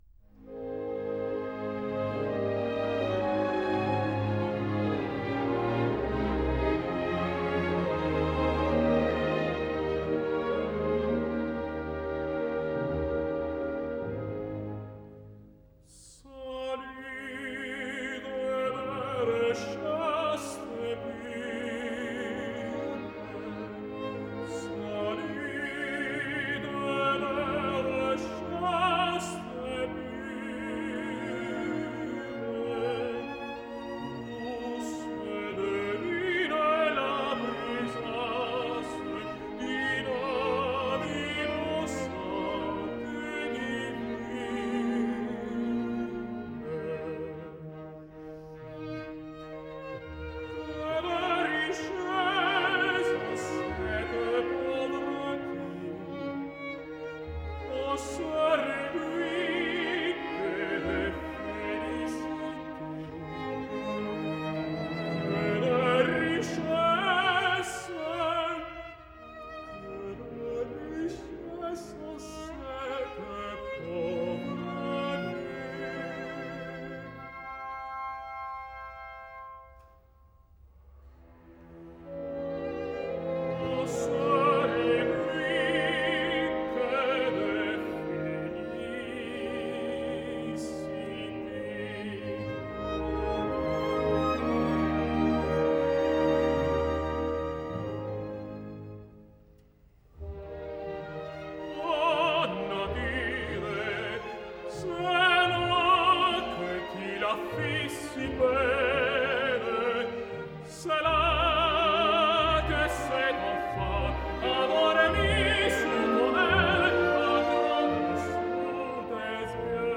Marcelo Álvarez sings Faust:
He was not exactly an exciting interpreter (neither musically nor as an actor), but vocally much superior to most other tenors of his generation.